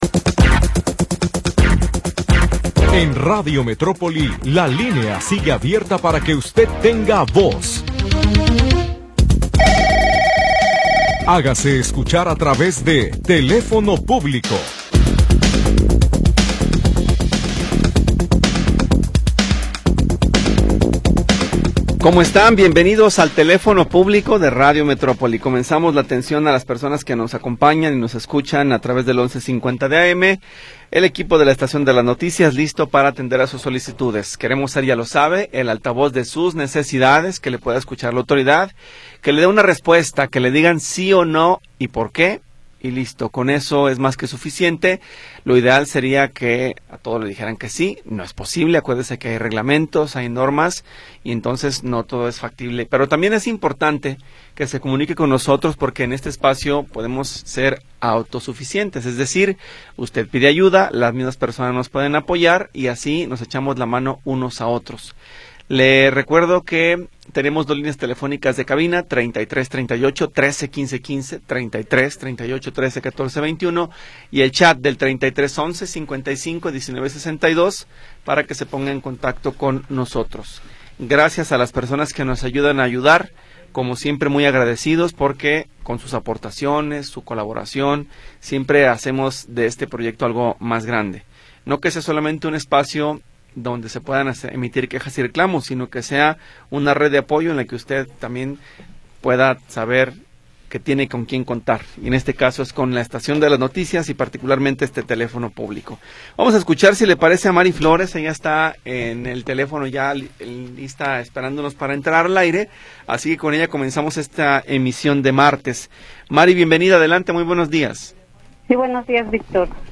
Programa transmitido el 1 de Julio de 2025.